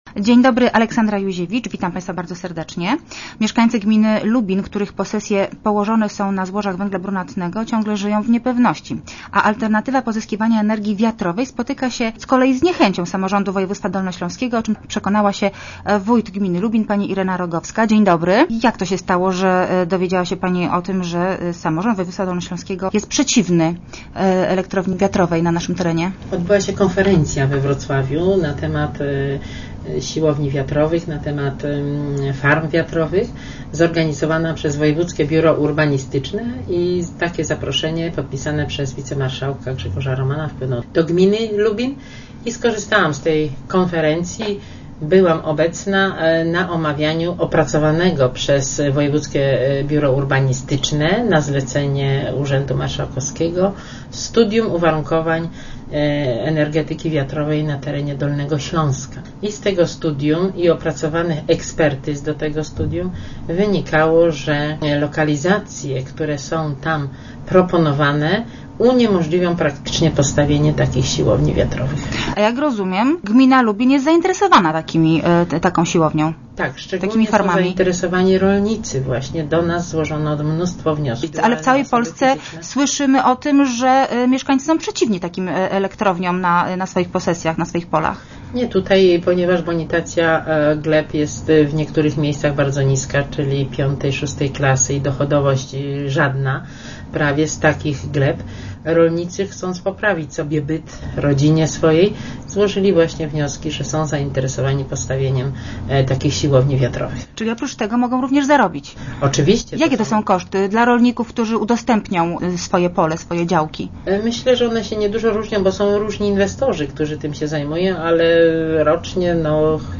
Start arrow Rozmowy Elki arrow Rogowska: Rolnicy chcą wiatraków.
- Wynika z nich, że lokalizacje, które są tam proponowane uniemożliwią postawienie takich siłowni wiatrowych - twierdzi gość audycji.